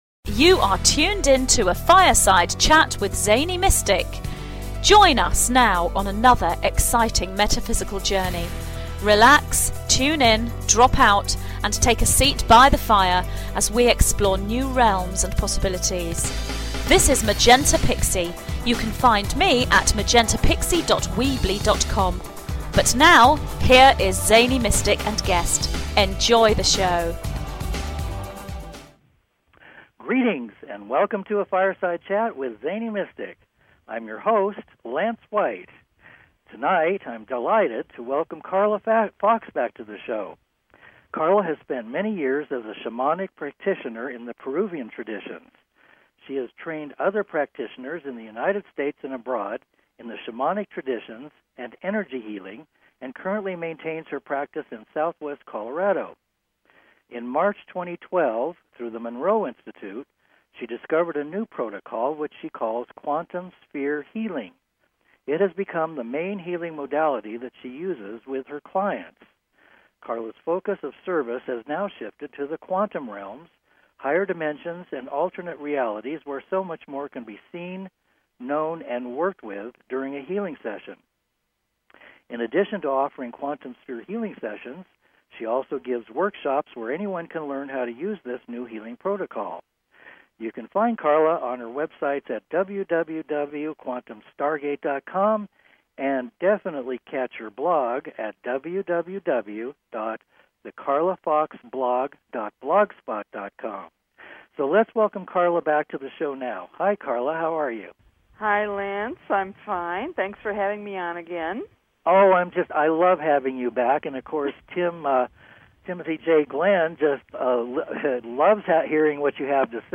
Show Headline A Fireside Chat Show Sub Headline